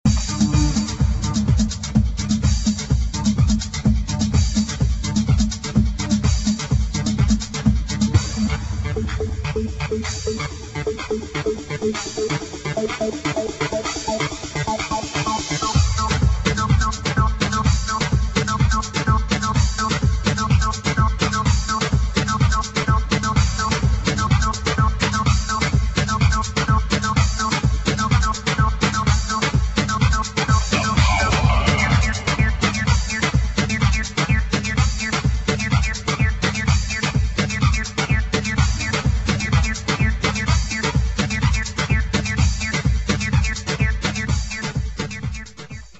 HOUSE | DISCO